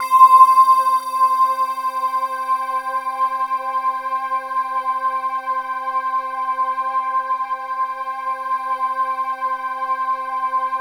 Index of /90_sSampleCDs/Infinite Sound - Ambient Atmospheres/Partition C/03-CHIME PAD
CHIMEPADC5-L.wav